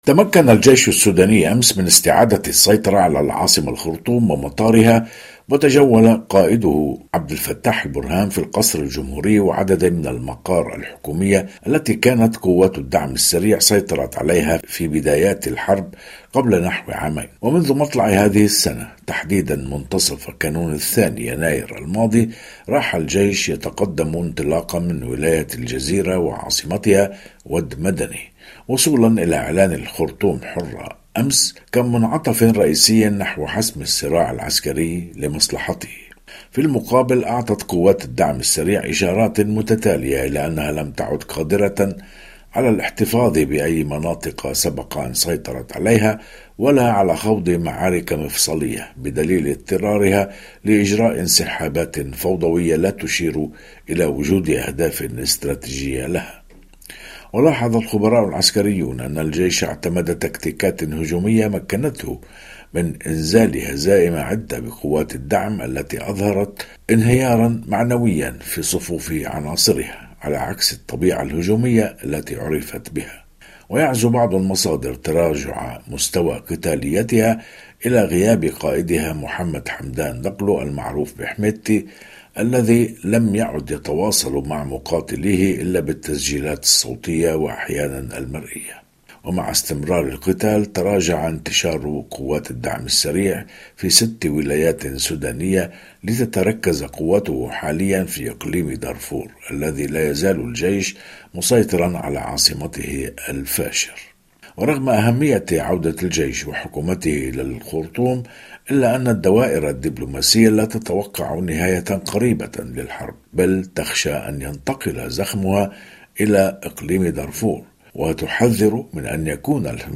فقرة إخبارية تتناول خبراً أو حدثاً لشرح أبعاده وتداعياته، تُبَثّ على مدار الأسبوع عند الساعة الرابعة والربع صباحاً بتوقيت باريس ويُعاد بثها خلال الفترات الإخبارية الصباحية والمسائية.